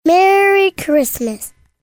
Genre: Blues.
L-MERRY-CHRISTMAS-CHILD-A.mp3